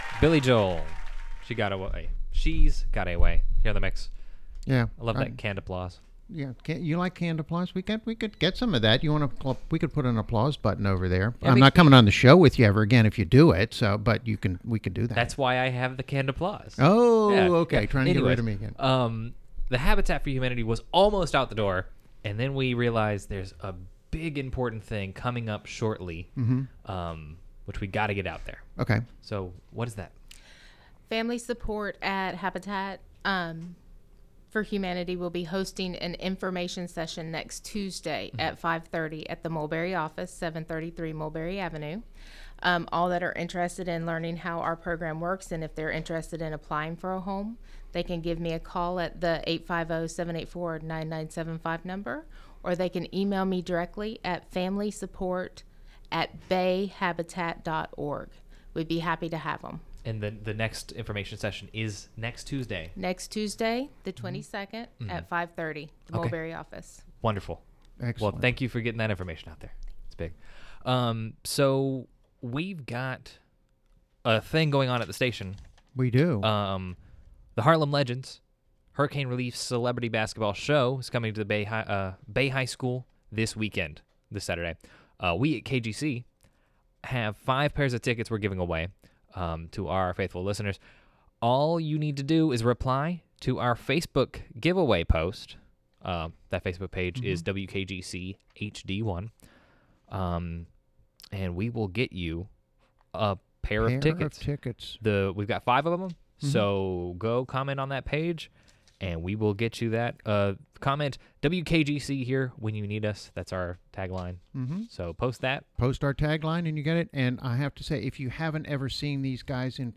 WKGC Studio